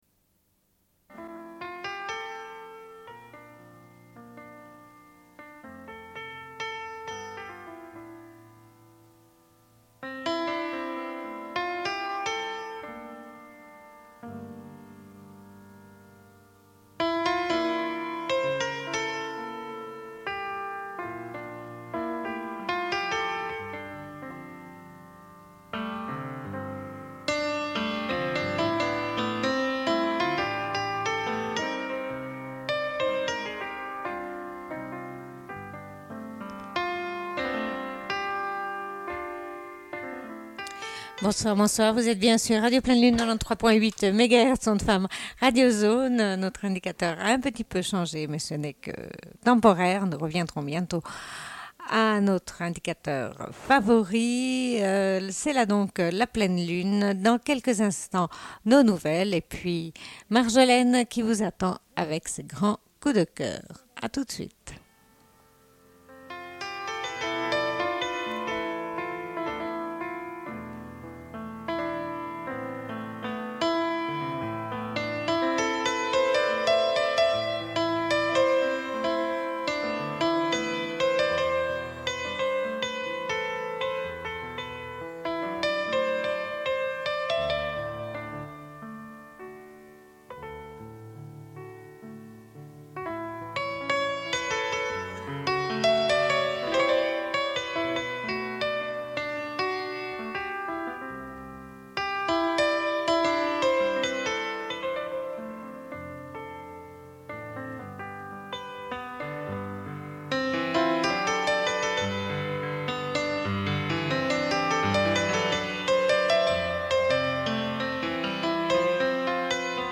Bulletin d'information de Radio Pleine Lune du 20.09.1995 - Archives contestataires
Une cassette audio, face B